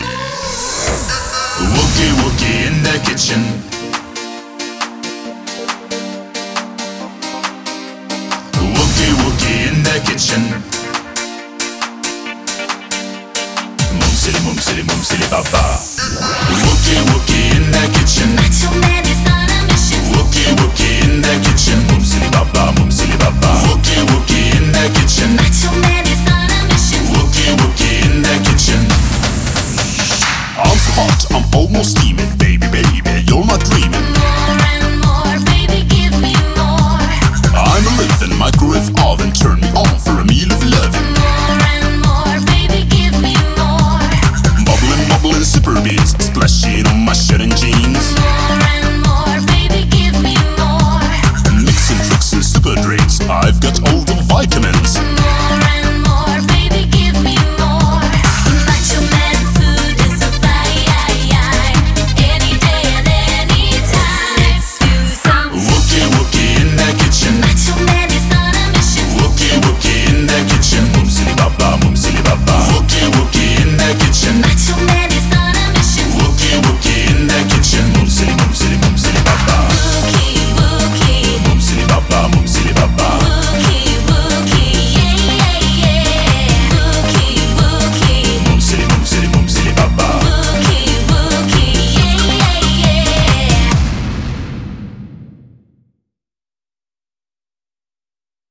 BPM137
Audio QualityPerfect (High Quality)
Comentarios[EURODANCE POP]
Song type: DDR edit